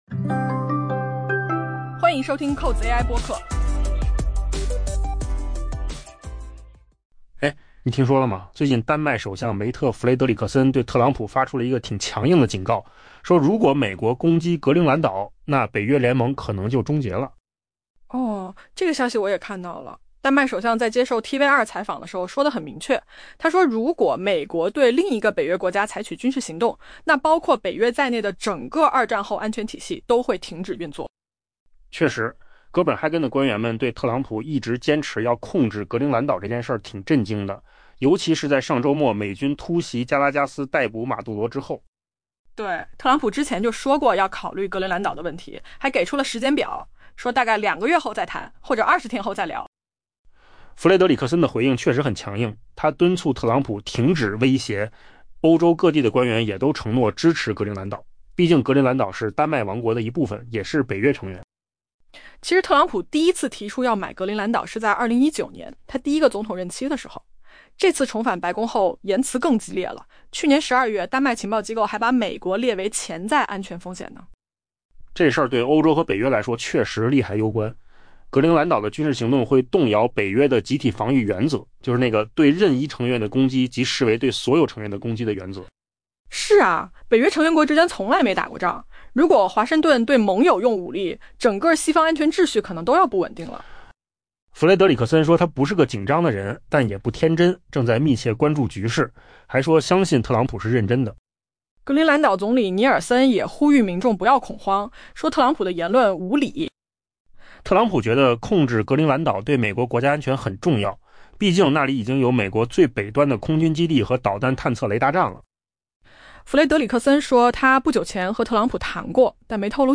AI 播客：换个方式听新闻 下载 mp3 音频由扣子空间生成 丹麦首相梅特·弗雷德里克森 （Mette Frederiksen） 表示，如果美国总统特朗普攻击丹麦的格陵兰岛，那将意味着北约联盟的终结。